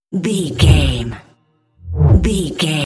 Whoosh deep fast
Sound Effects
Fast
dark
futuristic
intense